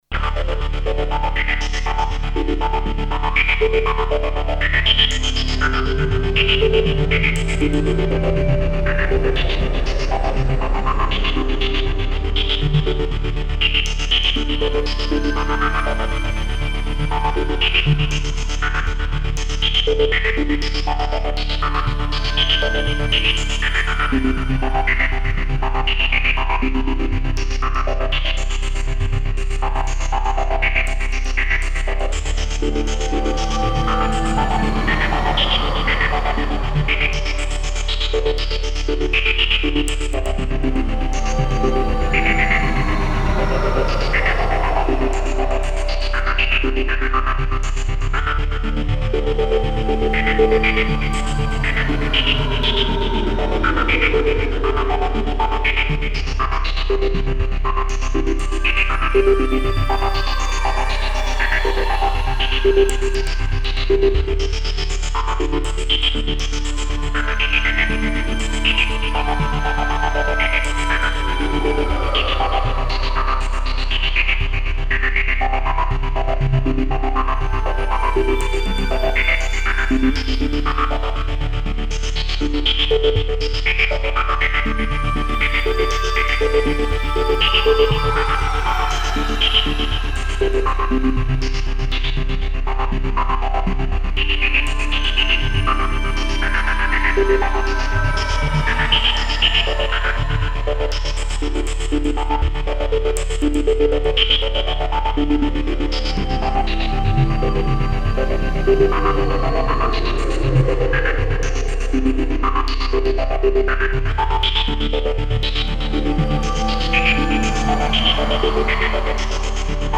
Tempo: 60 bpm / Datum: 19.06.2018